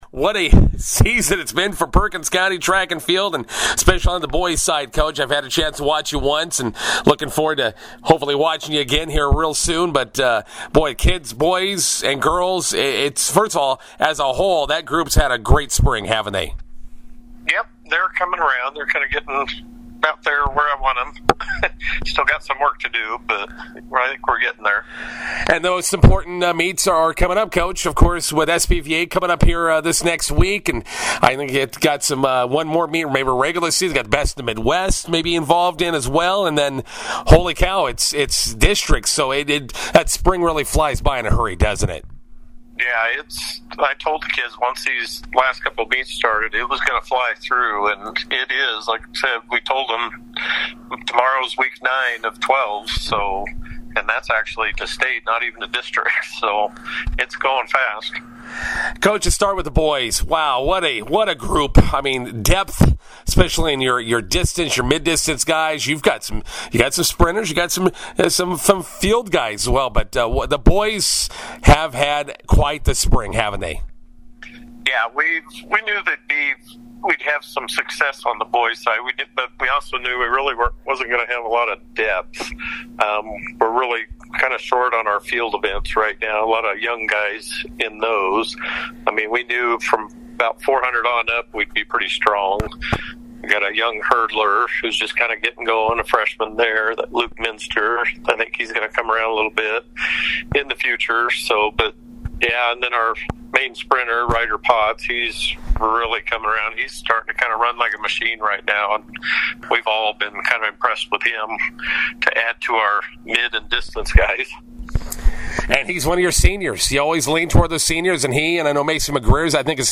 INTERVIEW: Perkins County Track and Field preparing for SPVA meet this Friday.